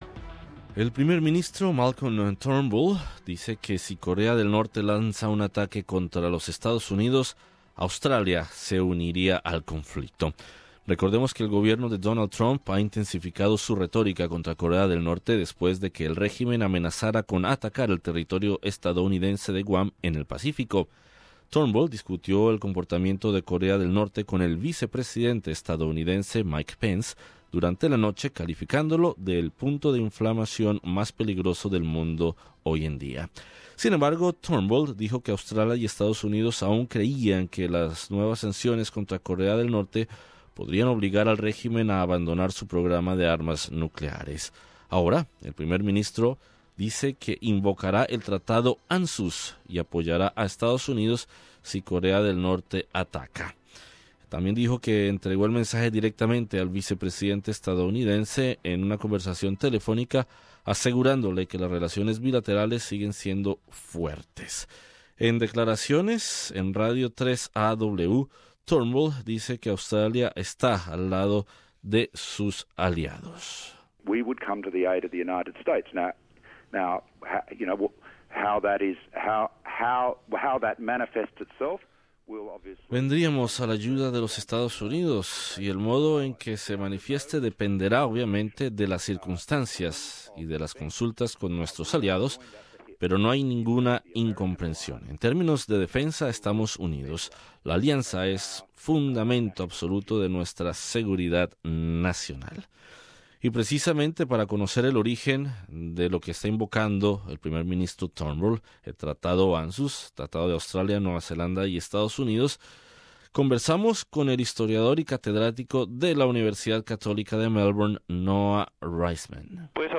Entrevista con el historiador y catedrático de la Universidad Católica de Melbourne